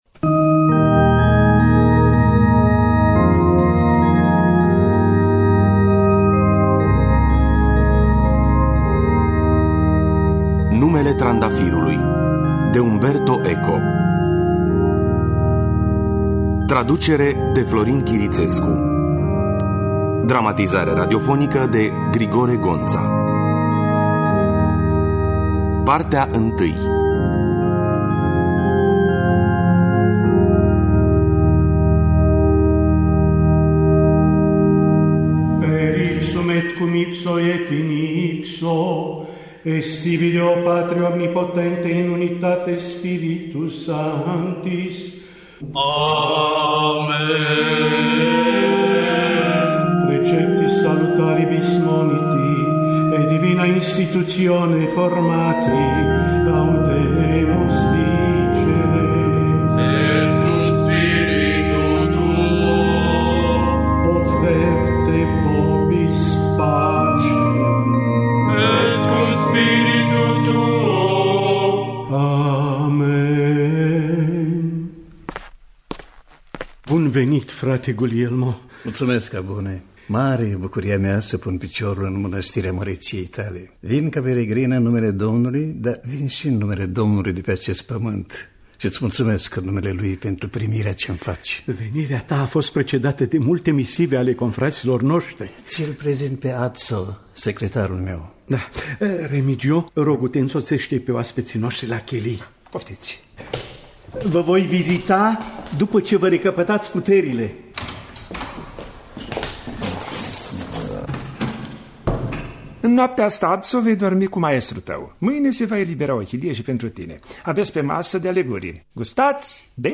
Numele trandafirului de Umberto Eco – Teatru Radiofonic Online
Adaptarea radiofonică şi regia artistică
Muzica originală